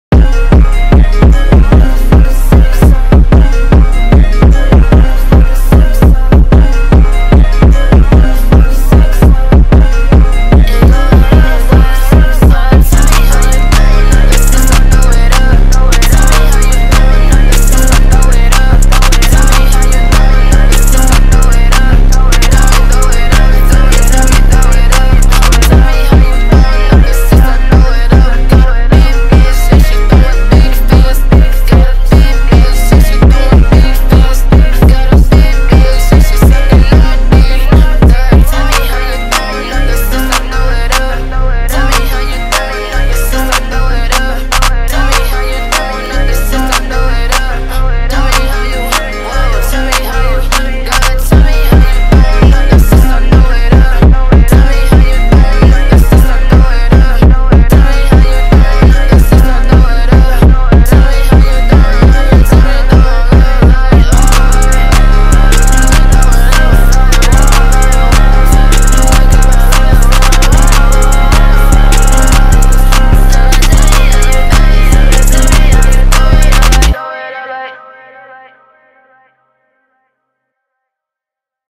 сочетая хип-хоп и мелодичный соунд